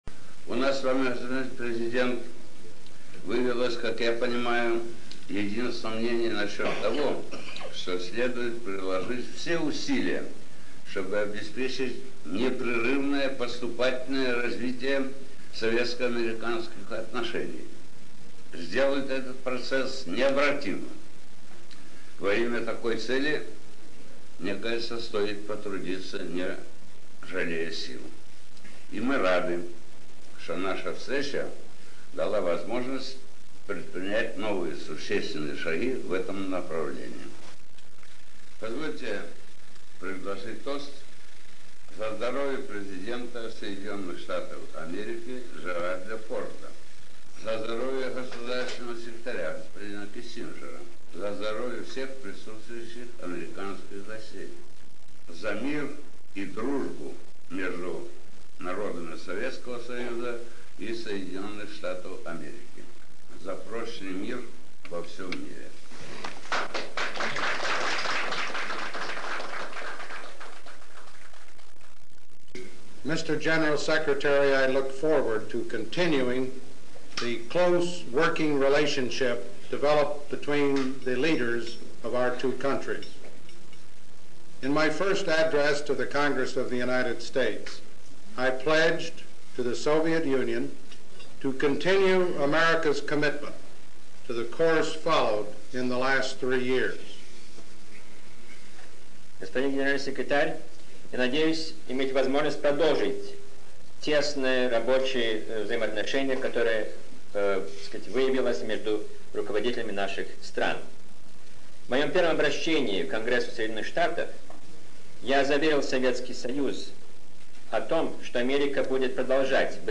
Финальная часть речи Леонида Брежнева и заключительная часть речи Джеральда Форда на ужине во Владивостоке, 24 ноября 1974.